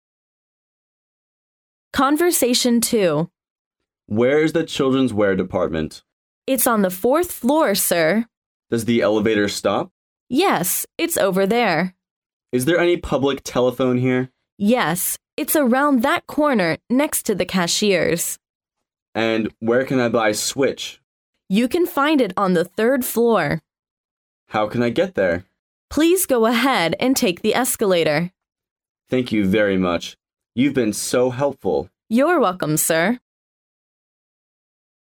Conversation 2